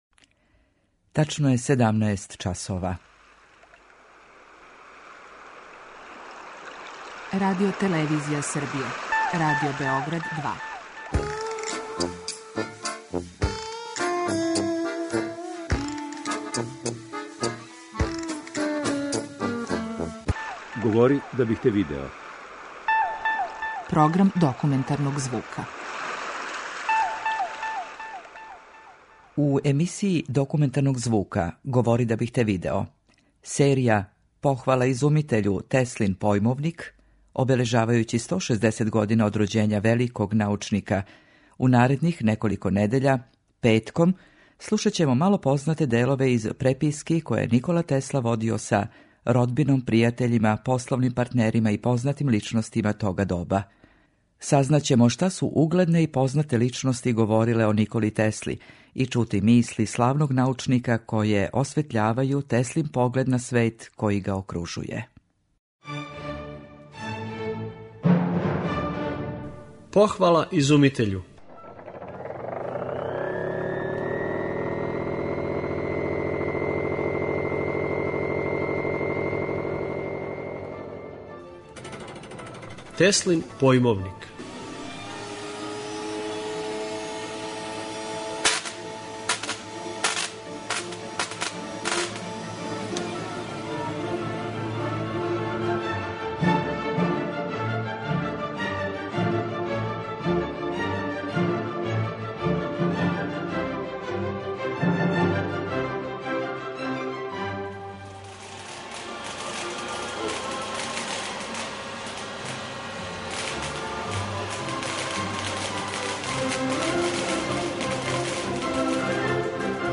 Документарни програм: серија 'Похвала изумитељу - Теслин појмовник'